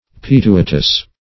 Search Result for " pituitous" : The Collaborative International Dictionary of English v.0.48: Pituitous \Pi*tu"i*tous\, a. [L. pituitosus: cf. F. pituiteux.]
pituitous.mp3